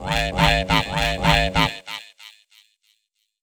Techno / Voice / VOICEFX215_TEKNO_140_X_SC2(L).wav